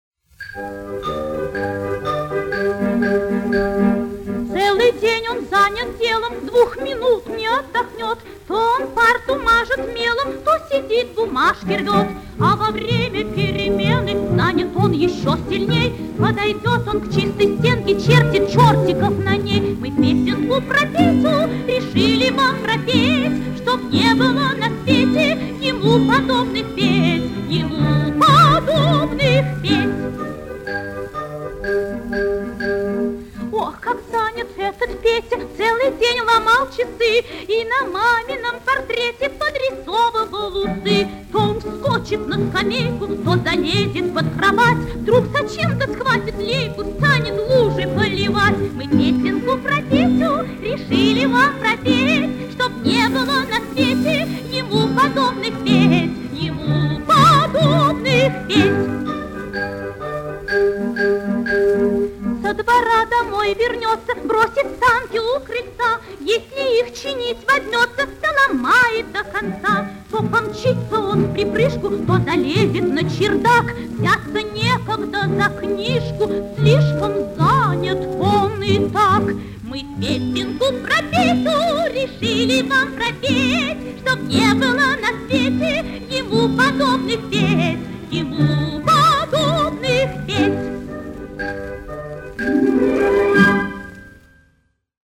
инстр. анс.
Матрица 25637 (патефонная)